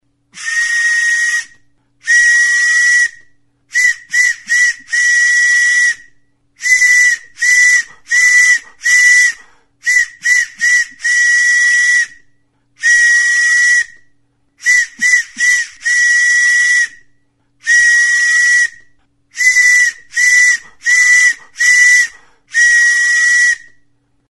Music instrumentsTXULUBITA
Aerophones -> Flutes -> Fipple flutes (one-handed)
Registered with this musical instrument.
Makal makilatxoarekin egindako txulubita.